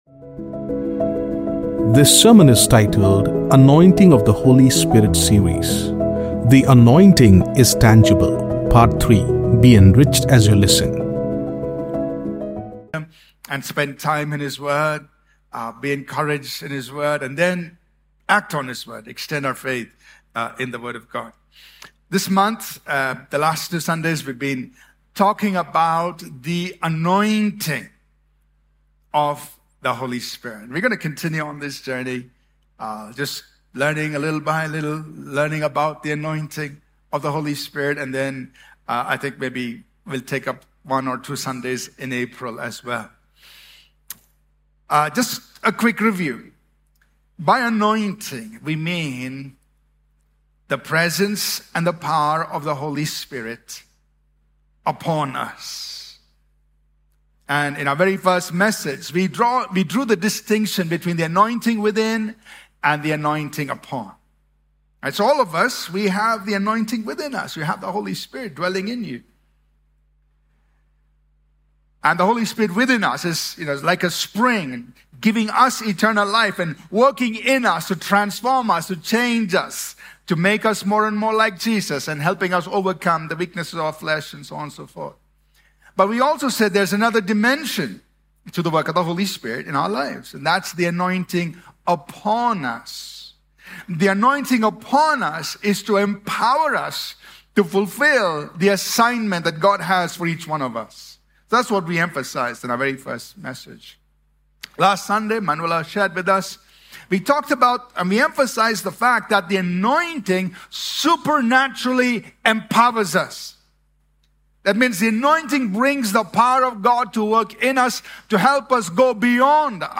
Sermons preached at All Peoples Church, Bangalore, India.